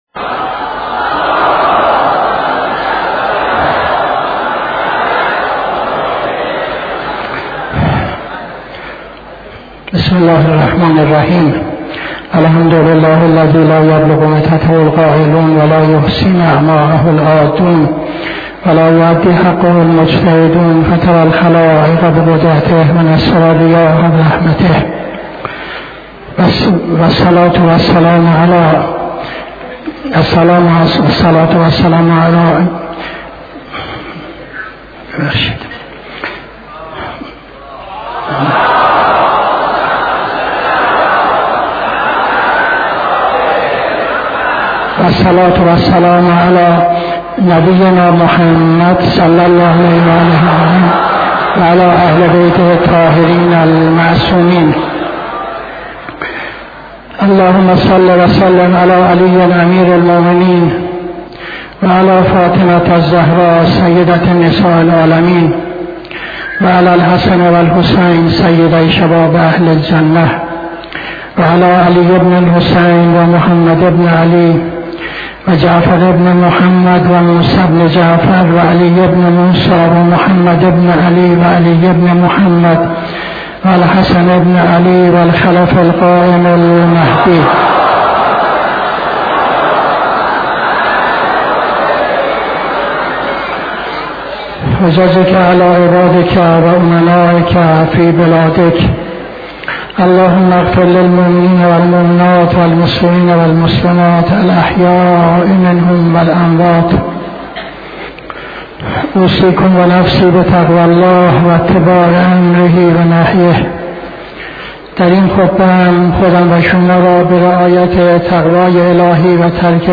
خطبه دوم نماز جمعه 10-10-78